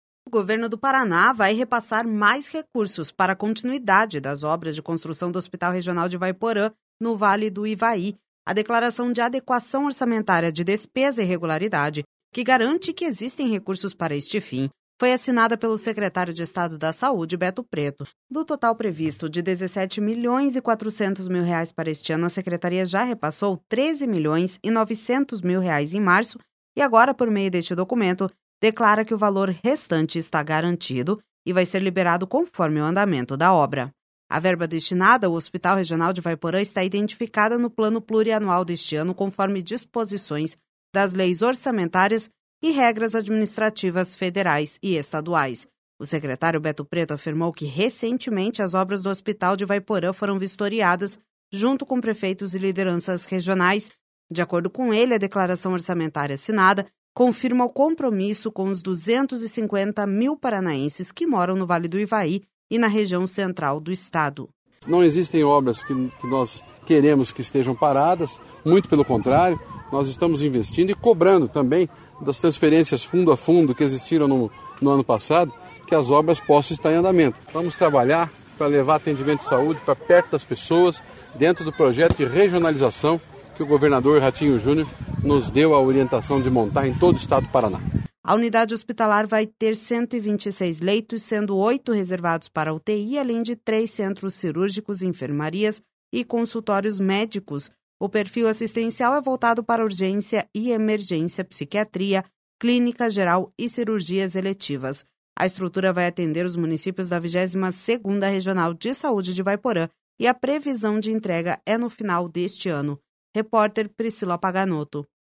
De acordo com ele, a Declaração Orçamentária assinada confirma o compromisso com os 250 mil paranaenses que moram no Vale do Ivaí e na Região Central do Estado// SONORA BETO PRETO//A unidade hospitalar vai ter 126 leitos, sendo oito reservados para a UTI, além de três centros cirúrgicos, enfermarias e consultórios médicos.